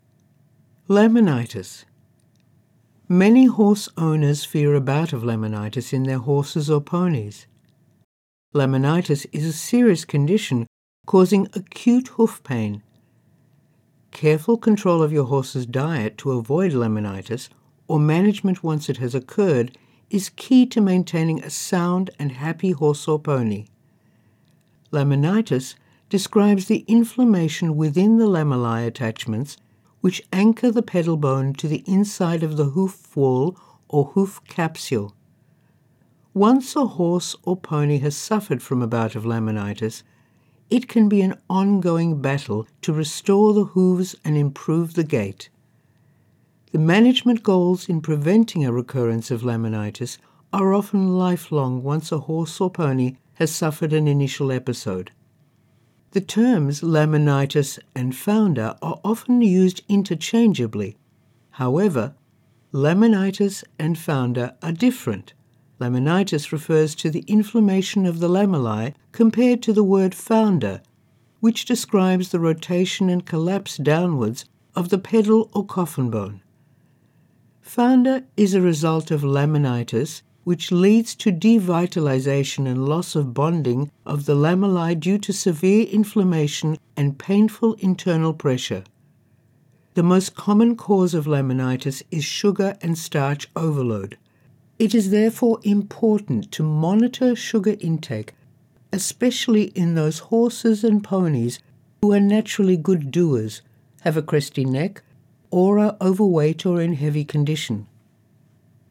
Female
English (Australian)
My voice overs are confident, warm, conversational, expressive, engaging, versatile and clear.
Medical Narrations